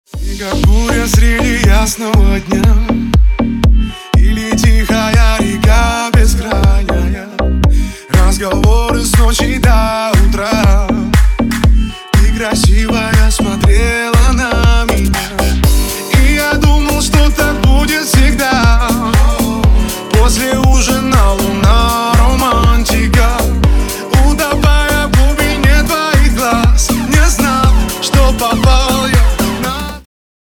• Качество: 320, Stereo
поп
веселые
dance
Club House
vocal